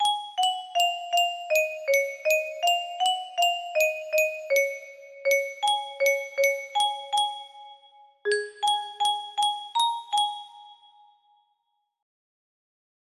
code: C# major